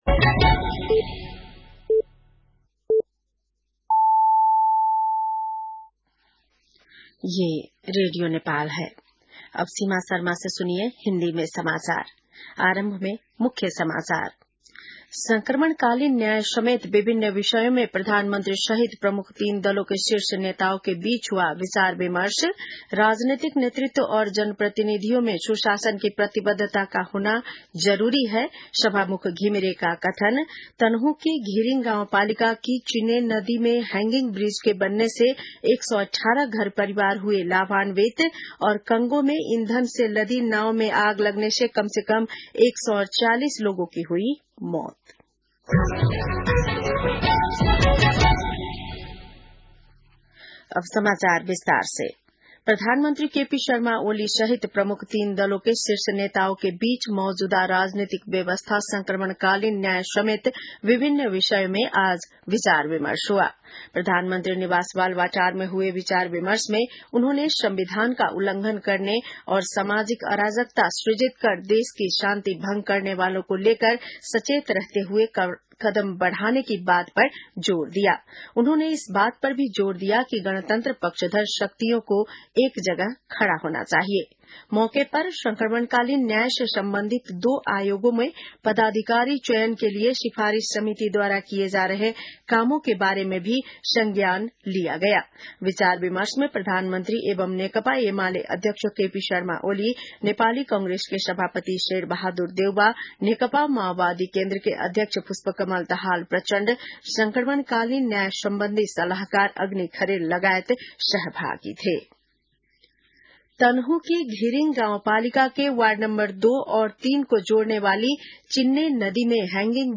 बेलुकी १० बजेको हिन्दी समाचार : ६ वैशाख , २०८२
10-pm-hindii-news.mp3